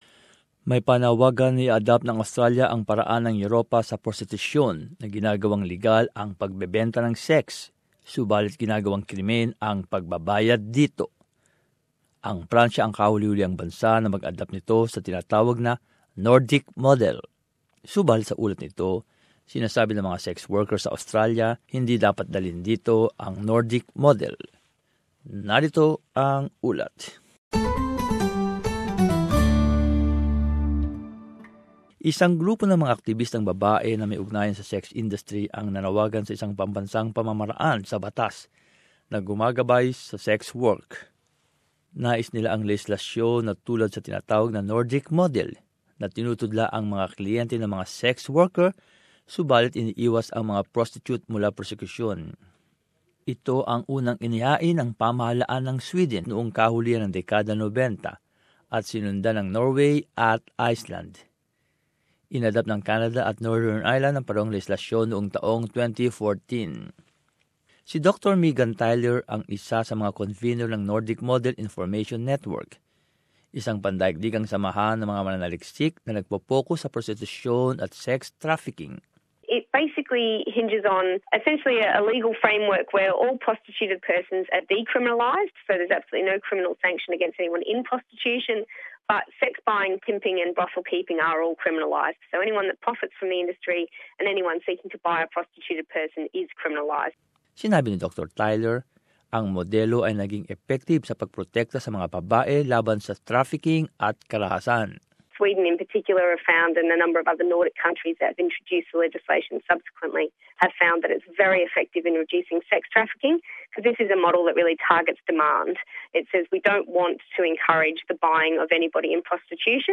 As this report shows, some Australian sex workers say it shouldn't be introduced here.